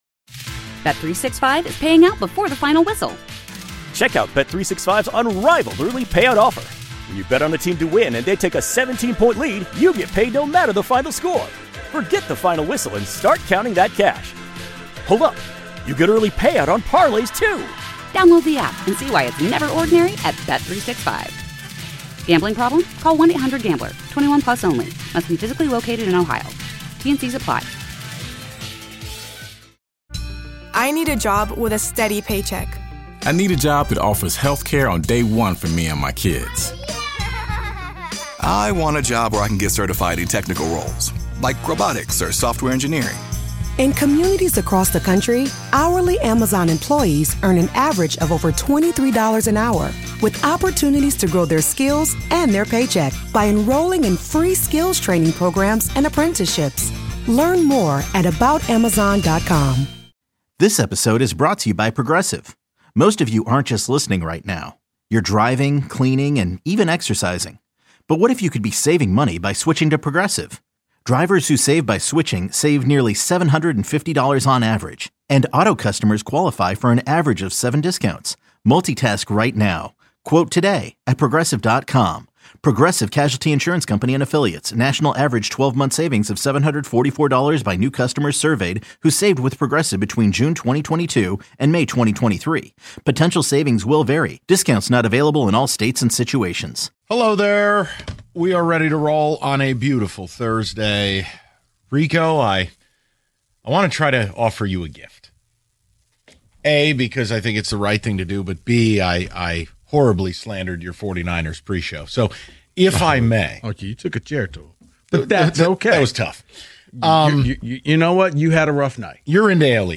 Best of Lions on 97.1 The Ticket: Monday reactions to win vs. Commanders. 11/10/25 - Victory Monday is back! Monday OverreactJims, Was Sunday more about Campbell or the Commanders? T.J. Lang in-studio.